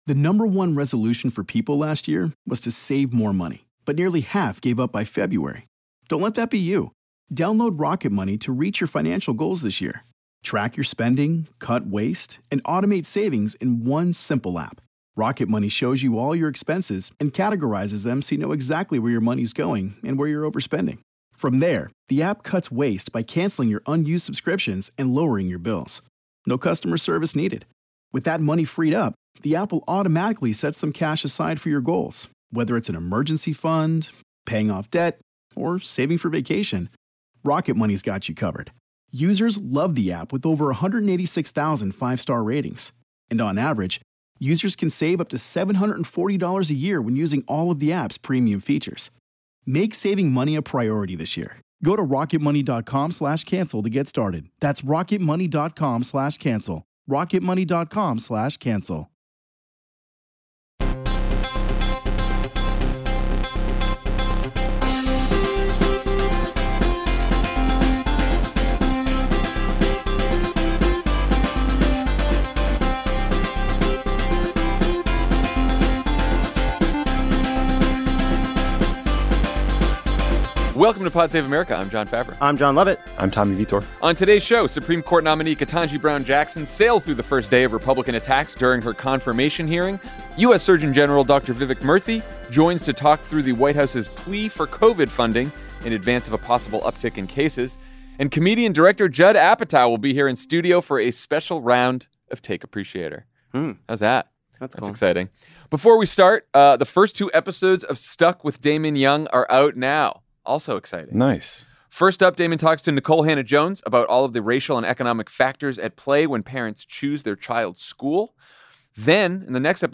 Supreme Court nominee Ketanji Brown Jackson sails through the first day of Republican attacks during her confirmation hearing, Surgeon General Vivek Murthy joins to talk through the White House’s plea for Covid funding in advance of a possible uptick in cases, and comedian/director Judd Apatow is in studio for a special round of Take Appreciator.